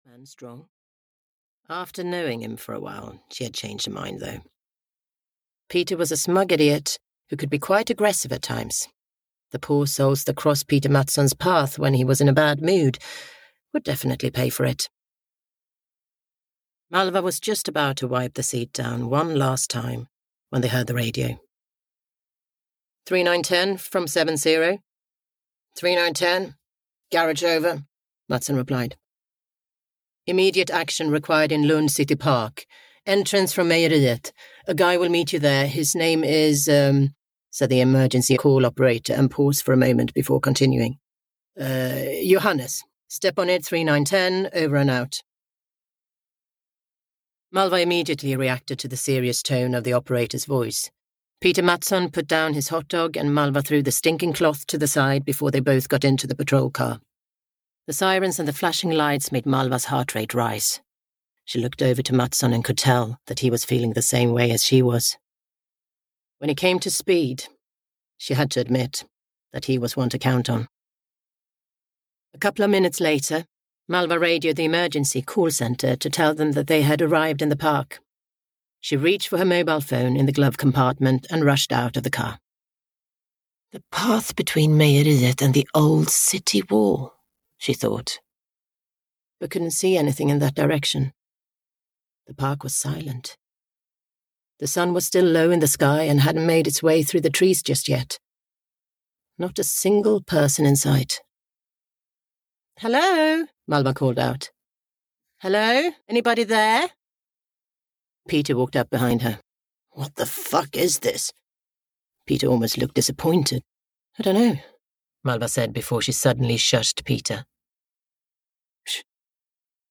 White Lilac (EN) audiokniha
Ukázka z knihy